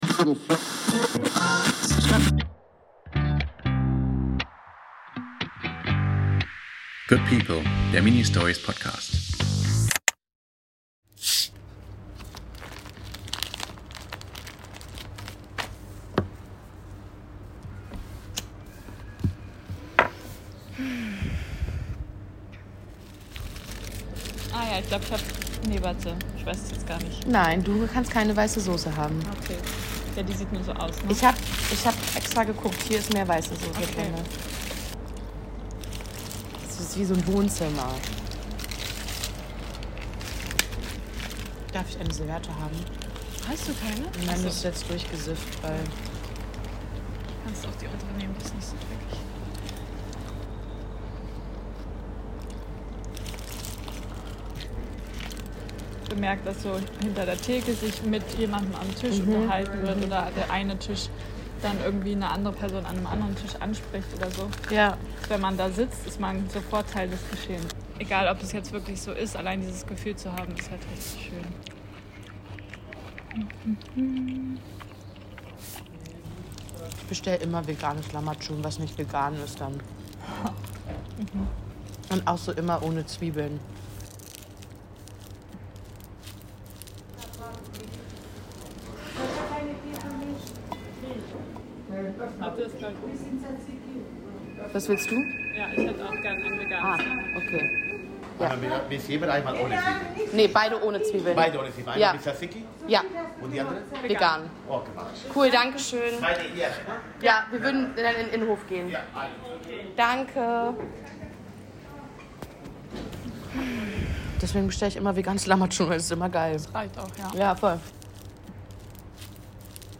Ein Abendessen zwischen Falafeltasche und dem besten Lahmacun der Stadt, zwischen zischenden Softgetränkdosen und raschelnder Alufolie, ein Treffen im Hinterhof und einer Begegnung am Tresen.